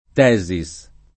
Tesis [ t $@ i S ] top. (Friuli)